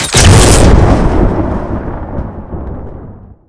lightningbolt.ogg